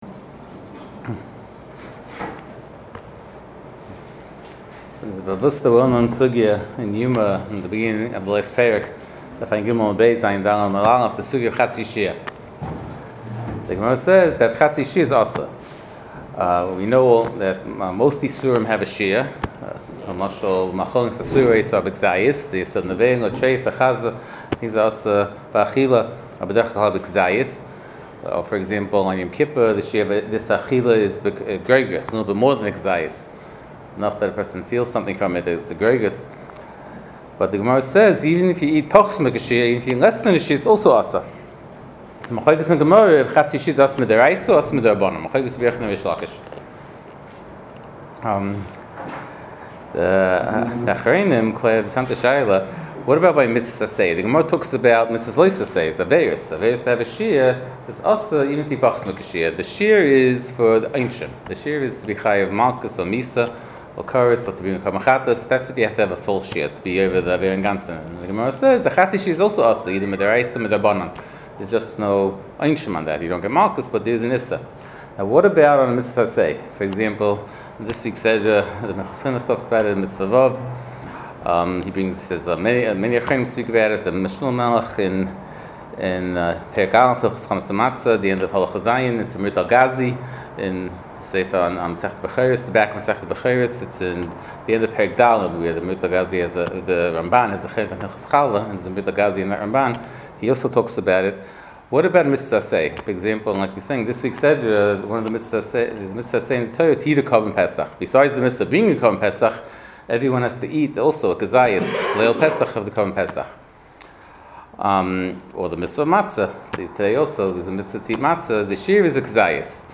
Shiur provided courtesy of Madison Art Shop.